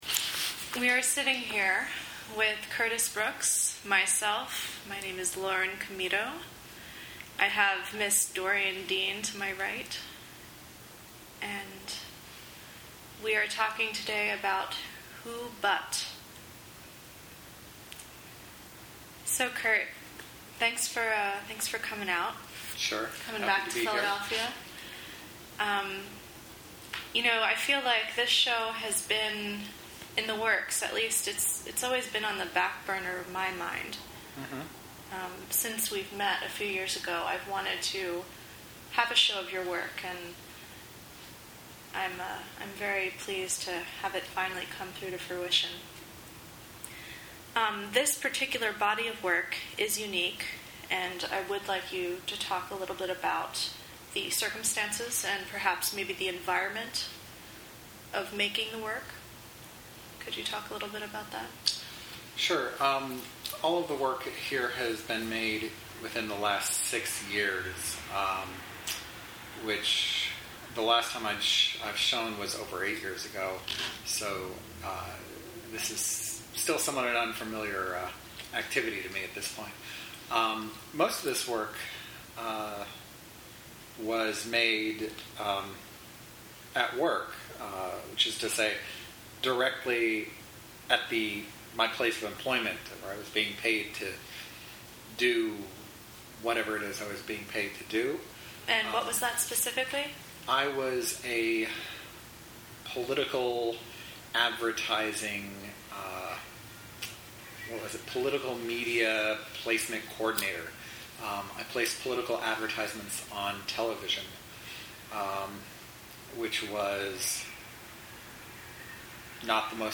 who-but-interview.mp3